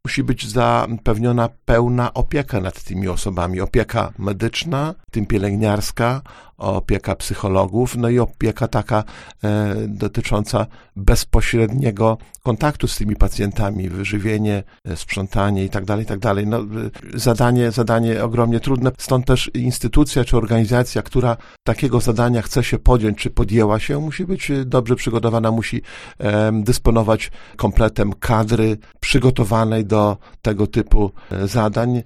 – Musi być zapewniona pełna opieka nad tymi osobami. Opieka medyczna, w tym pielęgniarska, opieka psychologów, opieka taka dotycząca bezpośredniego kontaktu z tymi pacjentami, wyżywienie, sprzątanie itd. Zdanie ogromnie, ogromnie trudne. Stąd instytucja, czy organizacja, która takiego zadania chce się podjąć, musi być dobrze przygotowana, dysponować kompletem kadry przygotowanej do takich zadań – mówi starosta.